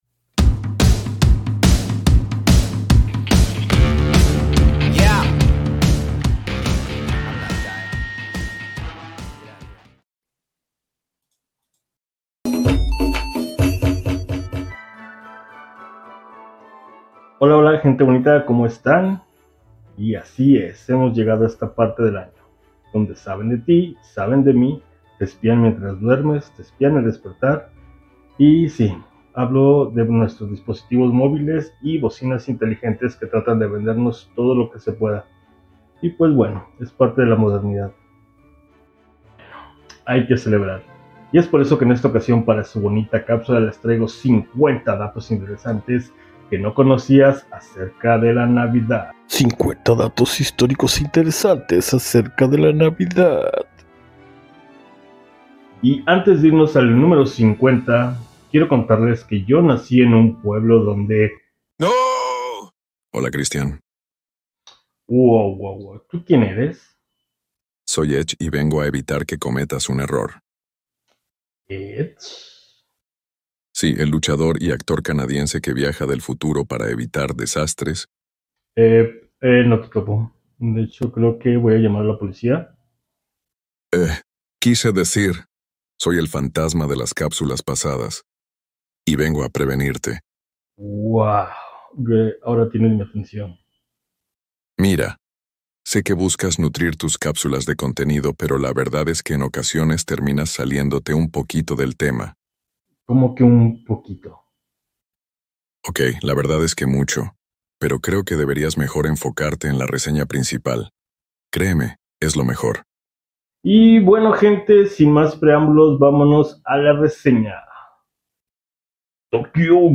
Nortcast Nortcast Un podcast de entretenimiento, tecnología y cultura pop. Presentado desde el Norte (Ciudad Juárez, Chihuahua).